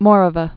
(môrə-və, mōrä-vä)